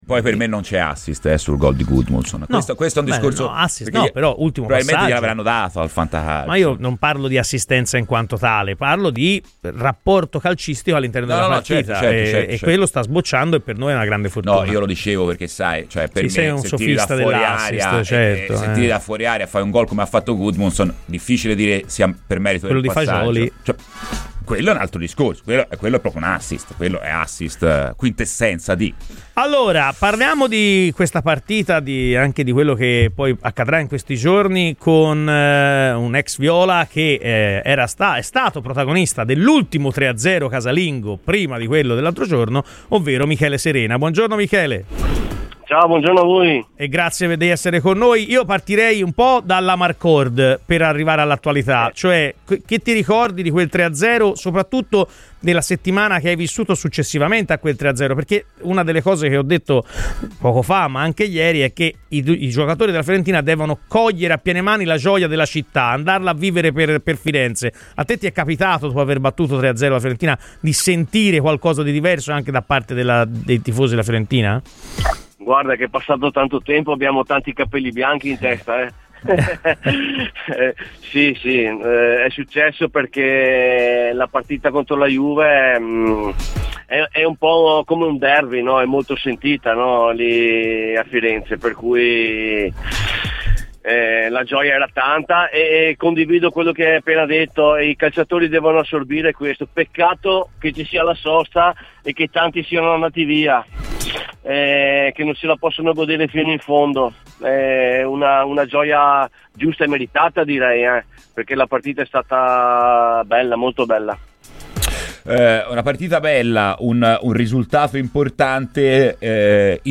Michele Serena, ex Fiorentina ospite su Radio FirenzeViola, ha parlato così del successo dei ragazzi di Raffaele Palladino contro la Juventus: "Quella contro la Juventus è una vittoria pesante, contro un rivale storico.